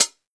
Closed Hats
HIHAT_THE_BATTLE.wav